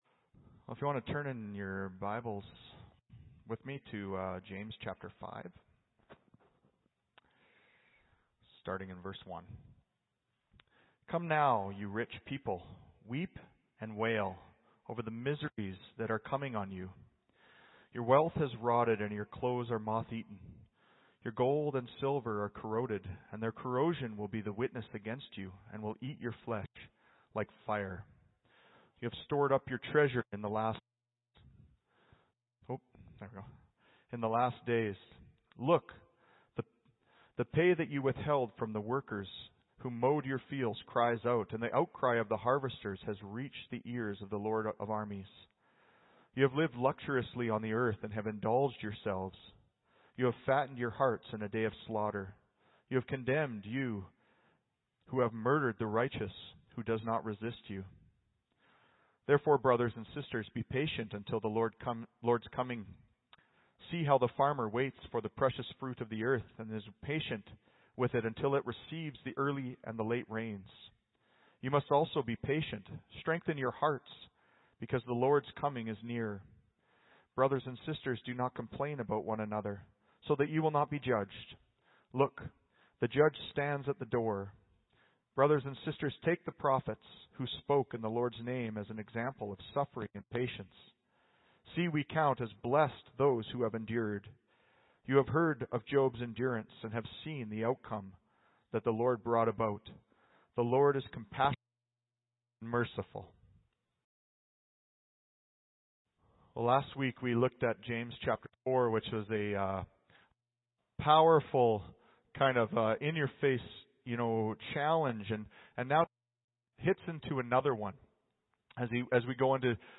Audio Sermon Library Christians in a Rich World.